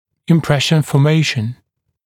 [ɪm’preʃn fɔː’meɪʃn][им’прэшн фо:’мэйшн]формирование впечатления